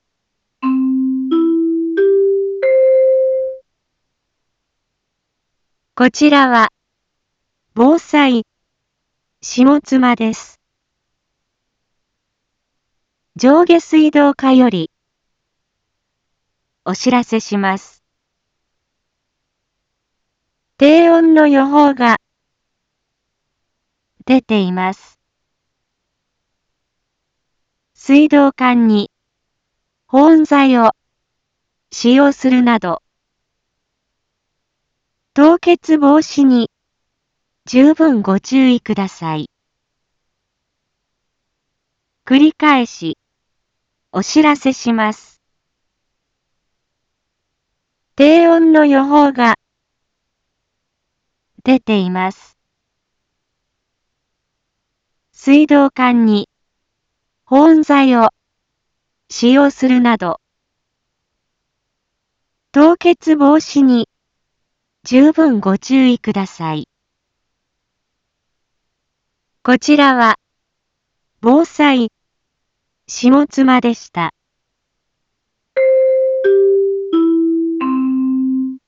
一般放送情報
Back Home 一般放送情報 音声放送 再生 一般放送情報 登録日時：2021-12-24 16:01:22 タイトル：凍結防止対策のお願いについて インフォメーション：こちらは、防災下妻です。